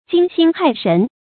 驚心駭神 注音： ㄐㄧㄥ ㄒㄧㄣ ㄏㄞˋ ㄕㄣˊ 讀音讀法： 意思解釋： 謂內心感到十分震驚。